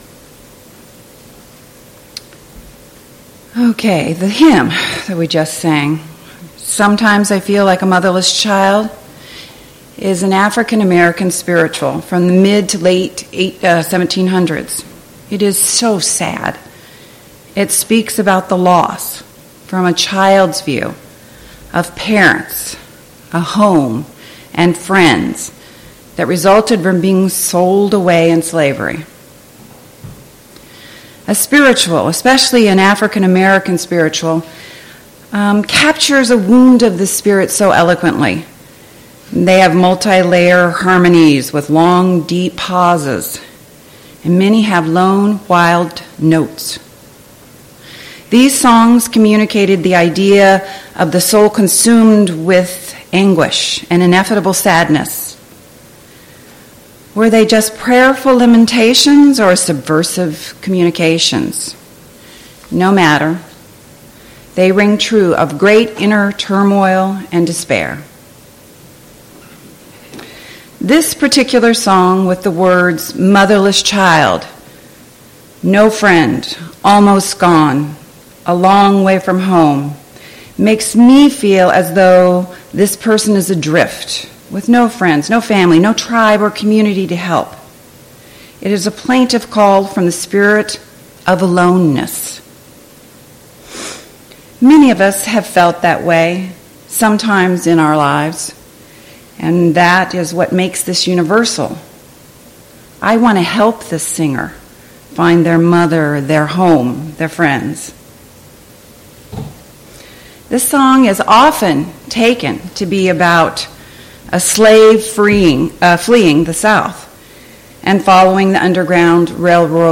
The sermon explores the human need for belonging and community.